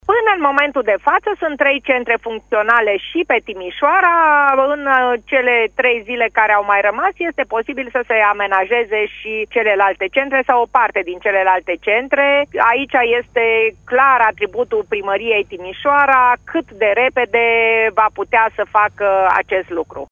Prefectul de Timiș a mai declarat pentru Radio Timișoara că unele centre de vaccinare sunt deja funcționale, inclusiv în municipiul reședință de județ și că primăria este direct responsabilă pentru ca acestea să fie funcționale odată cu debutul etapei a doua de vaccinare: